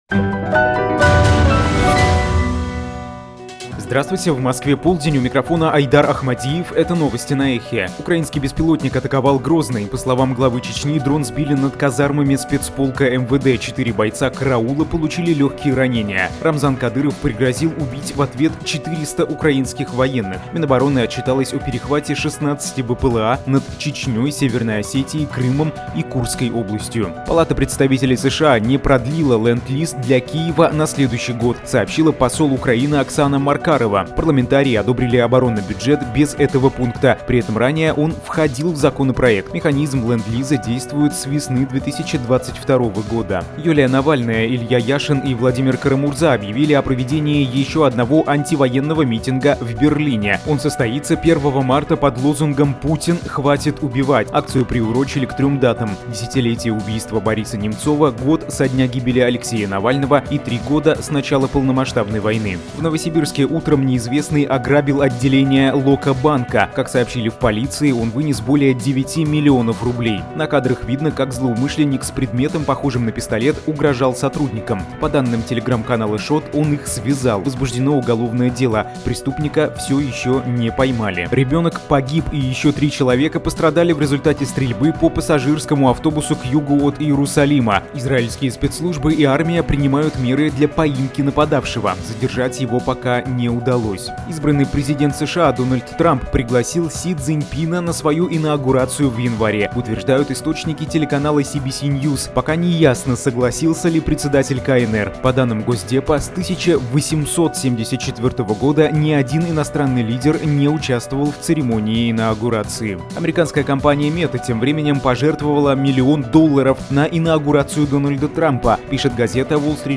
Свежий выпуск новостей